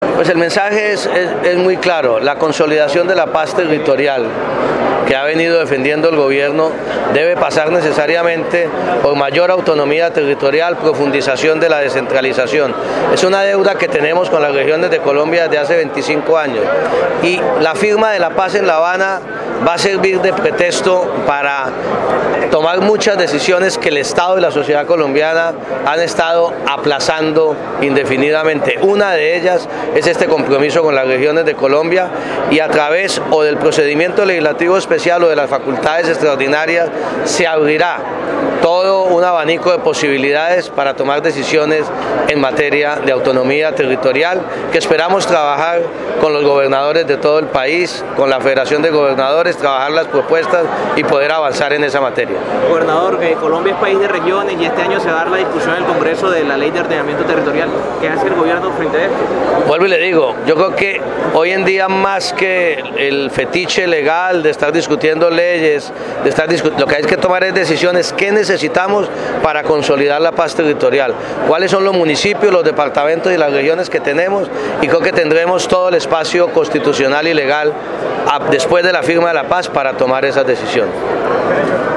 “No podemos pretender que haya paz en las regiones si seguimos centralizando todo desde Bogotá, hay es que hacerla con la gente, las comunidades y con las autoridades legítimamente constituidas. Con las facultades extraordinarias y el procedimiento legislativo especial que se viene tramitando en el Congreso tendremos una enorme oportunidad para avanzar en materia de ordenamiento territorial”, indicó el ministro del Interior durante su intervención en la Cumbre de Gobernadores.